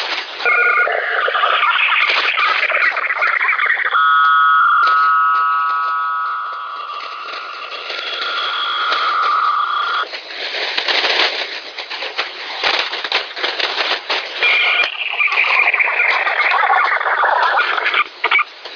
Начало » Записи » Радиоcигналы на опознание и анализ
Работа скремблера в сочетании с модемом BELL 103